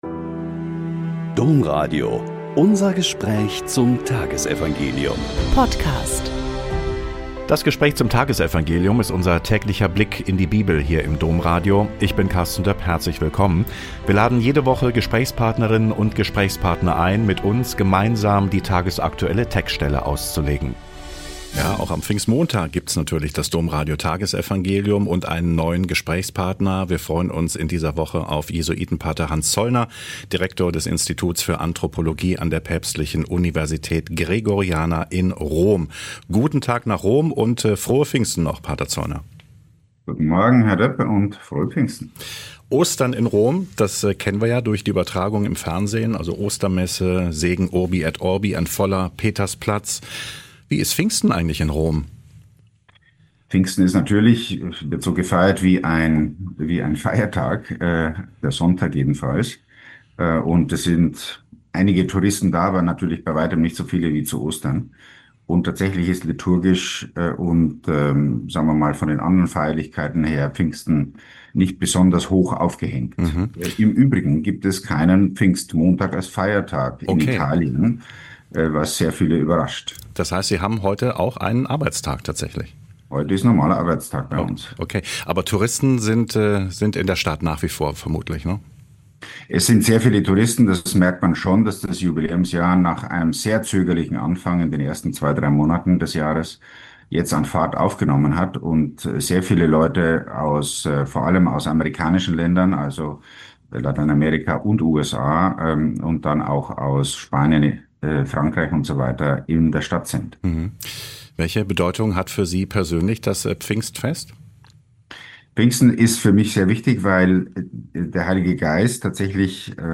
Joh 15,26 - 16,3.12-15 - Gespräch mit Pater Hans Zollner SJ